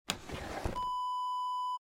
Dresser Drawer Open Wav Sound Effect #6
Description: The sound of a wooden dresser drawer being opened
Properties: 48.000 kHz 16-bit Stereo
A beep sound is embedded in the audio preview file but it is not present in the high resolution downloadable wav file.
Keywords: wooden, dresser, drawer, pull, pulling, open, opening
drawer-dresser-open-preview-6.mp3